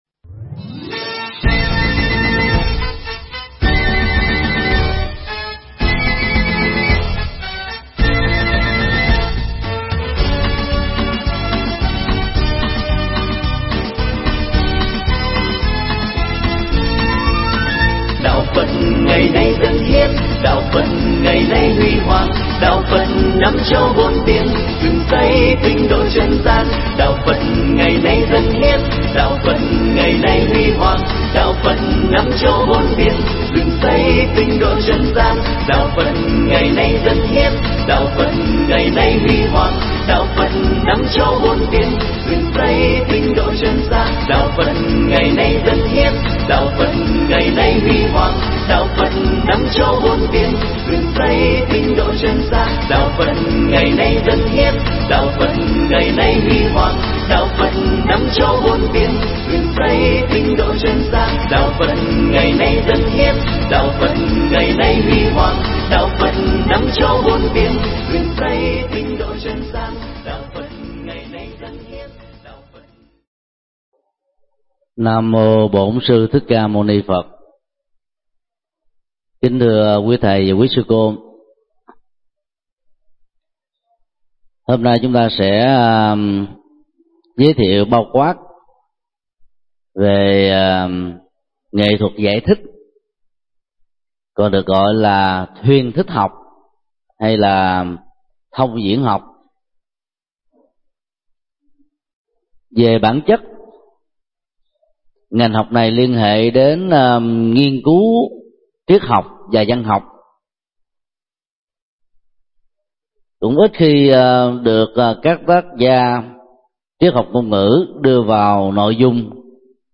Mp3 Thuyết Pháp Triết học ngôn ngữ Phật giáo 13: Nghệ thuật giải thích – Thầy Thích Nhật Từ giảng tại Học viện Phật giáo Việt Nam, ngày 3 tháng 7 năm 2012